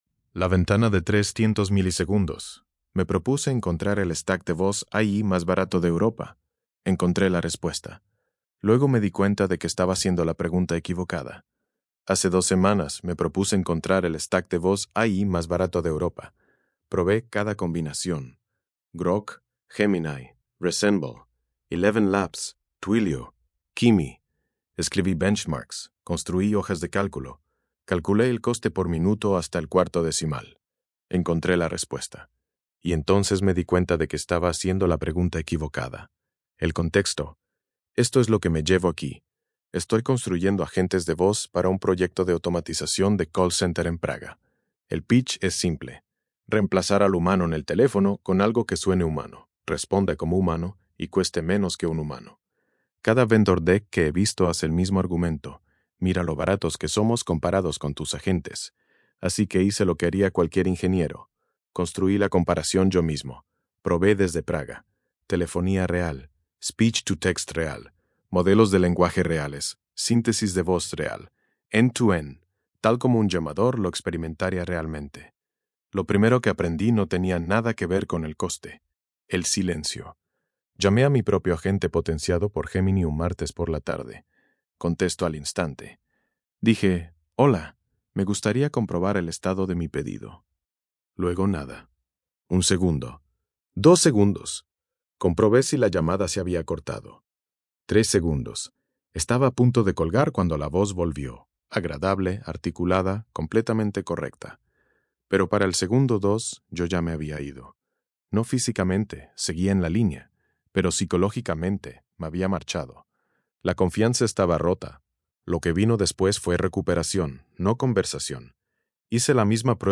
Versión de audio estilo podcast de este ensayo, generada con la API de voz de Grok.